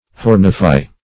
Hornify \Horn"i*fy\